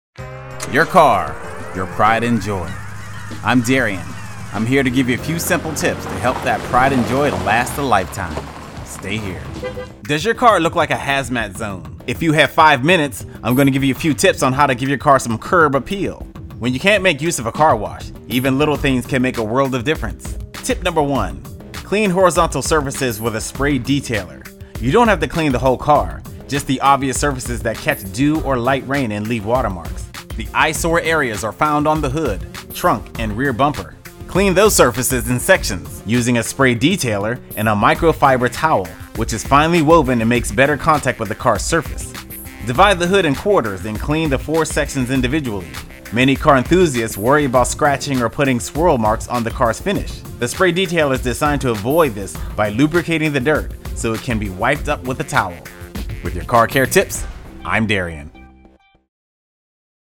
For your car enthusiasts, this daily two-minute radio feature contains advice